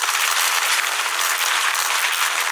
electric_sparks_lightning_loop2.wav